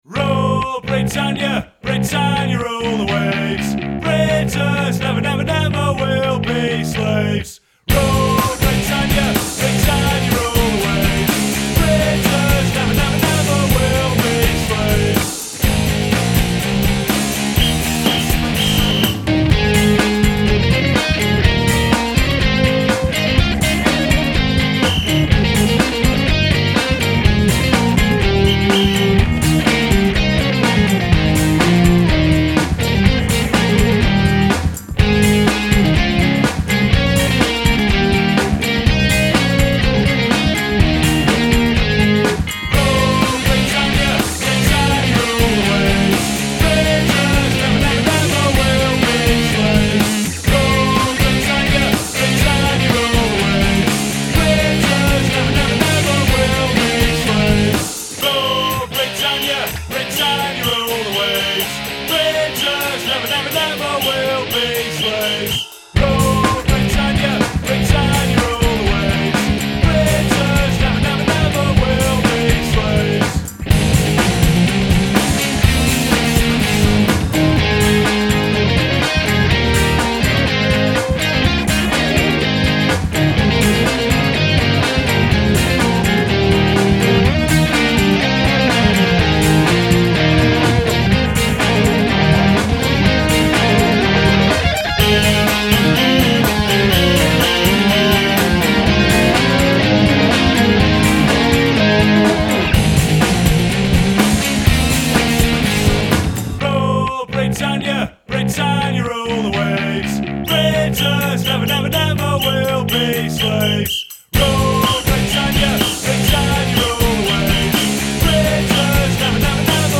Spielt eine moderne Version von „Rule, Britannia!“ ab